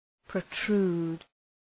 Προφορά
{prəʋ’tru:d}